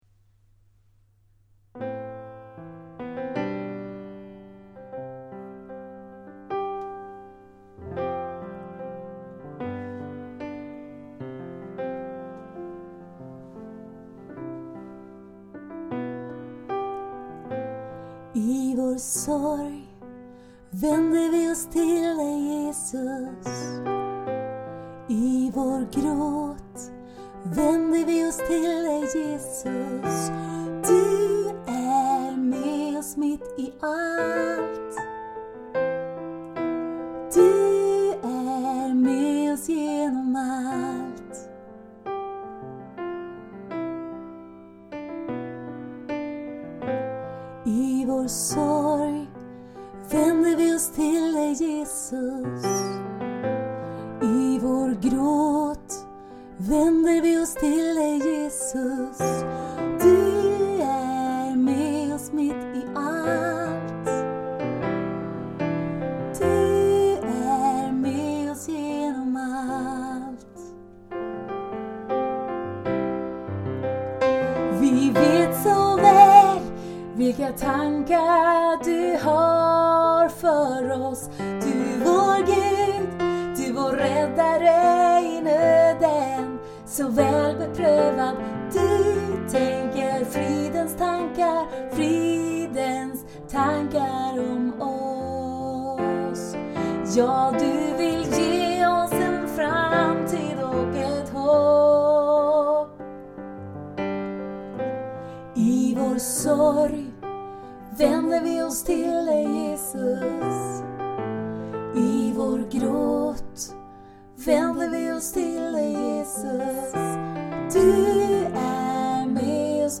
Här finns några moderna nya psalmer i enkla inspelningar.